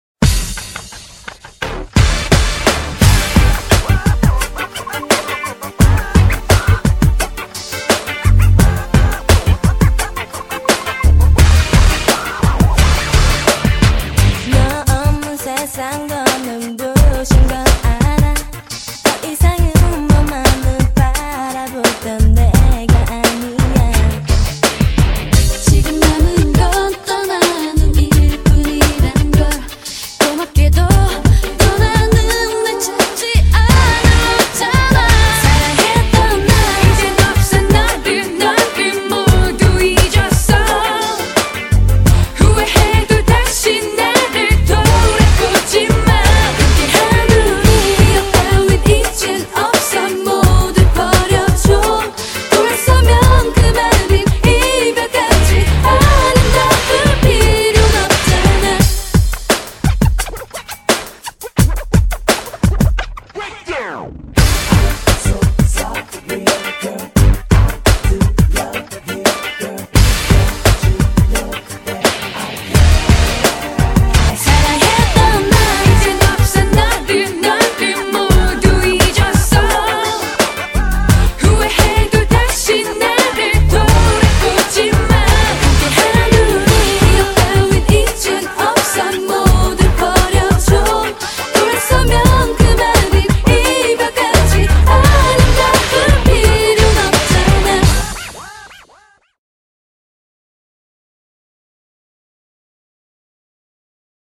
BPM86--1
Audio QualityPerfect (High Quality)